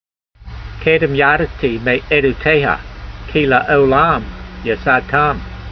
v152_voice.mp3